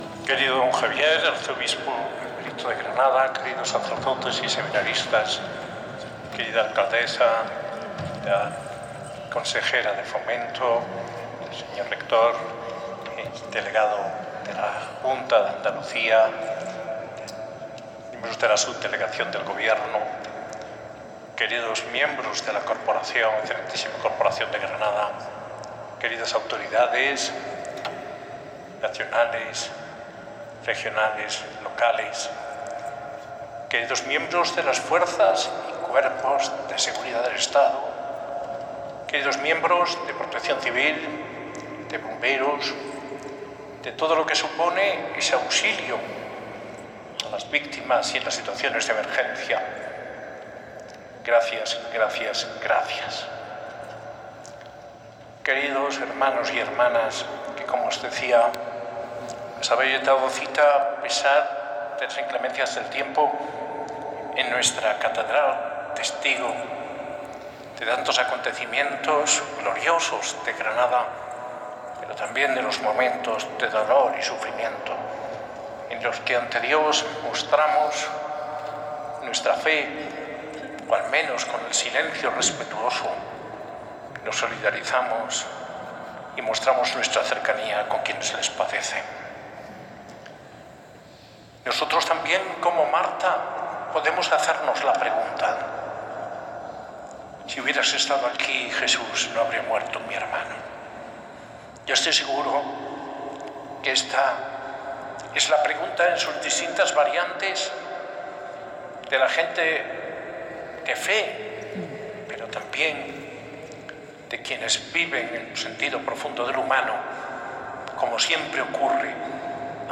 Homilía en la misa funeral en sufragio por las víctimas del accidente de tren en Adamuz (Córdoba) del arzobispo de Granada, Mons. José María Gil Tamayo, en la catedral el 23 de enero de 2026, con la asistencia de autoridades civiles y militares, y otras representaciones de la sociedad civil, concelebrada por el arzobispo emérito, Mons. Francisco Javier Martínez, y sacerdotes diocesanos.